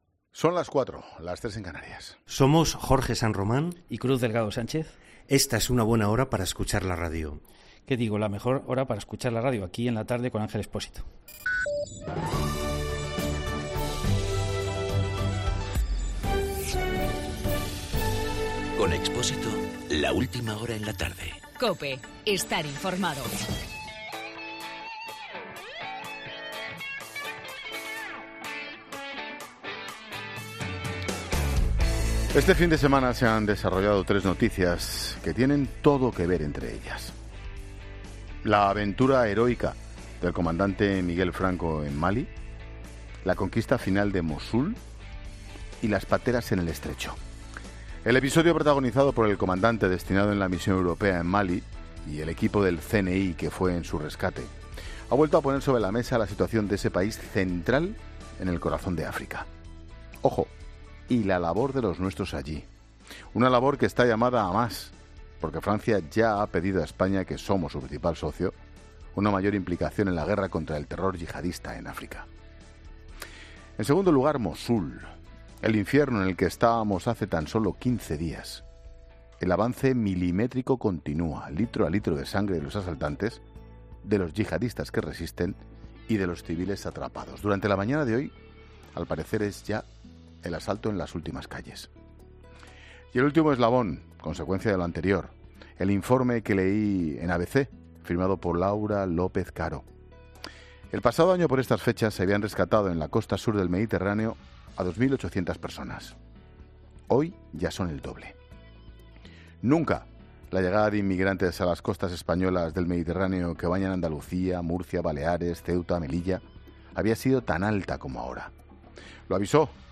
AUDIO: Monólogo de Ángel Expósito a las 16h. analizando el aumento de inmigrantes que están llegando a nuestras costas